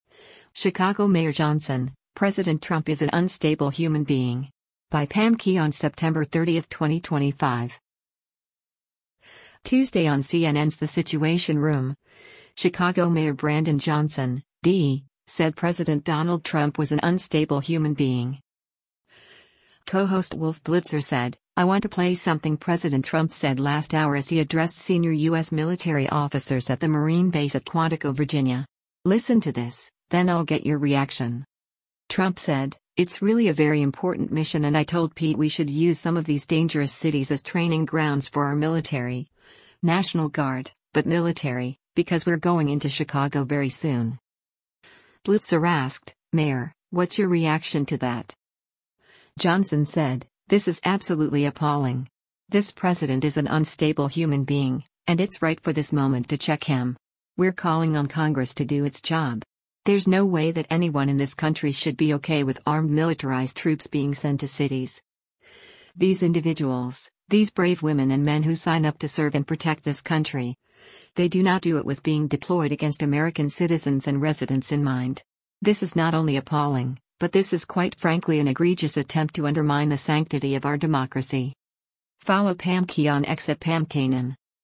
Tuesday on CNN’s “The Situation Room,” Chicago Mayor Brandon Johnson (D) said President Donald Trump was “an unstable human being.”